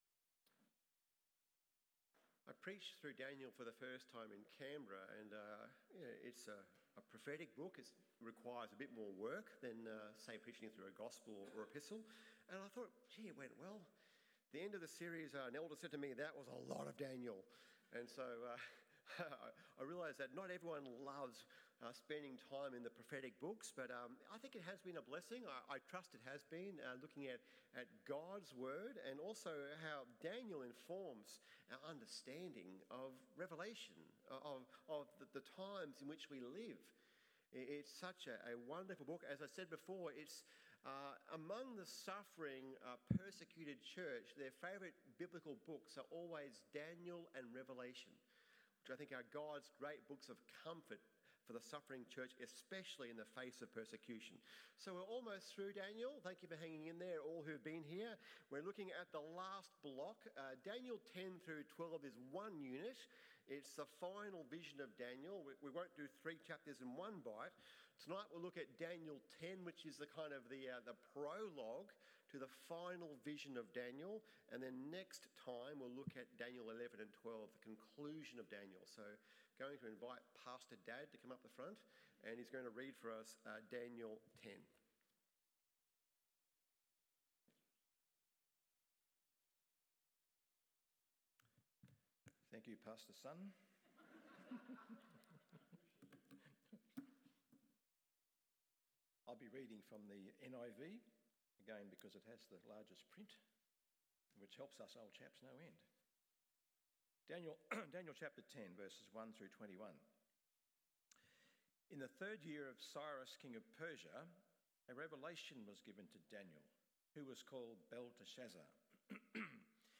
The Power Of Prayer PM Service